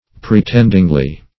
Search Result for " pretendingly" : The Collaborative International Dictionary of English v.0.48: Pretendingly \Pre*tend"ing*ly\, adv. As by right or title; arrogantly; presumptuously.